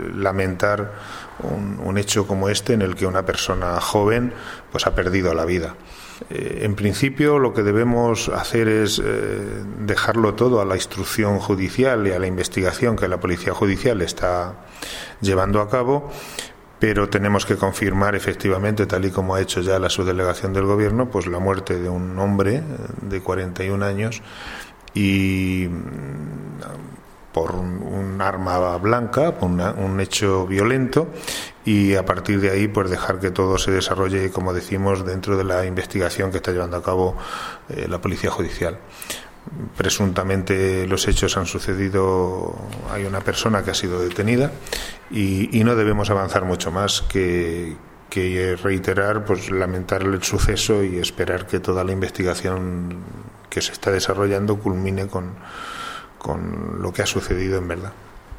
Declaración Alcalde Manzanares
declaracion_alcalde.mp3